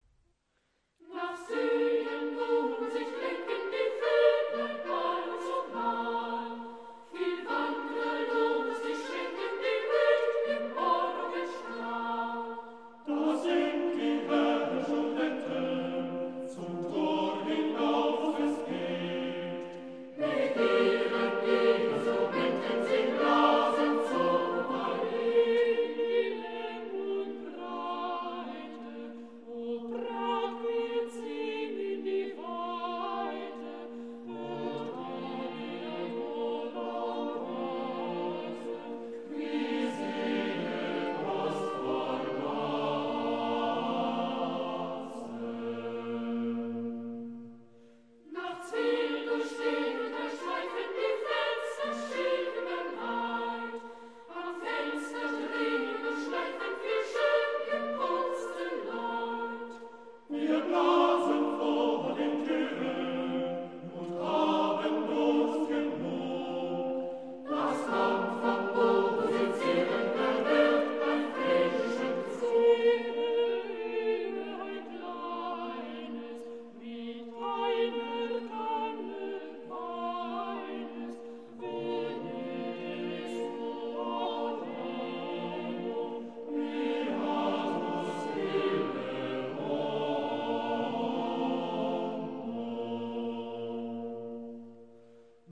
Eine Aufnahme mit dem "Kinderchor Hannover"